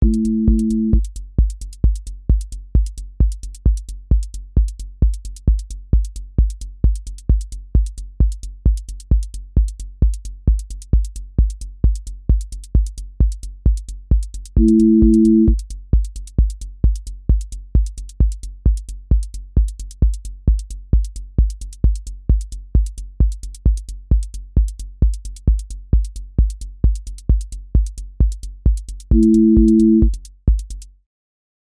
QA Listening Test techno Template: techno_hypnosis
• voice_kick_808
• voice_hat_rimshot
• voice_sub_pulse
• tone_brittle_edge
• motion_drift_slow
Techno pressure with driven motion